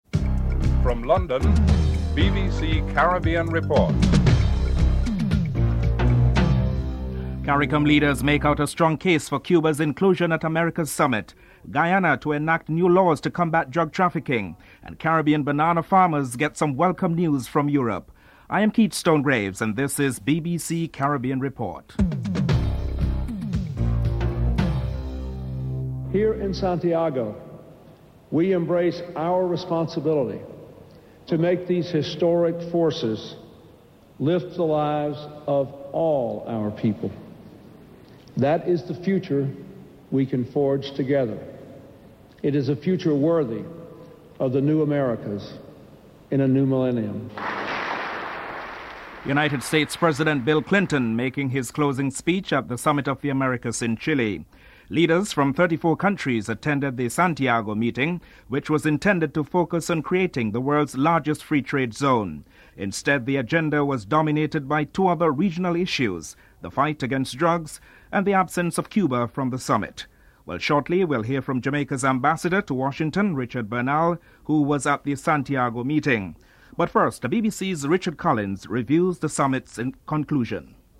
US President Bill Clinton makes the closing speech at the Summit of the Americas in Chile.
Agriculture Minister Cassius Elias comments (12:03-13:49)